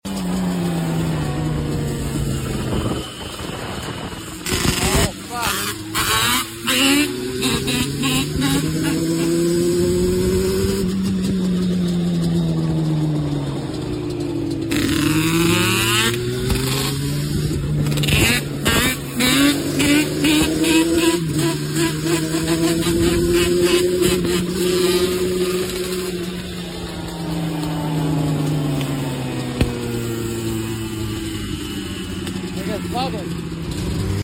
Band Play #2stroke #2strokelover #2t